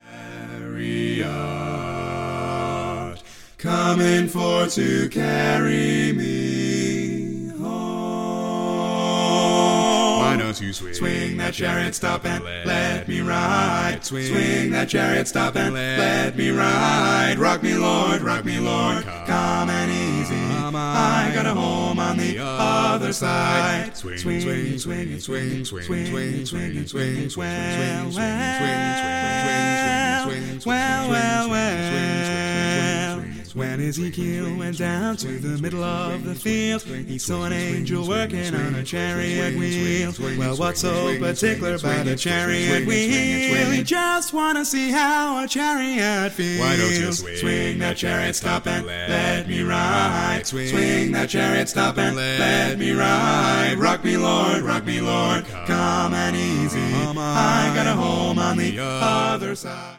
Full mix
Category: Male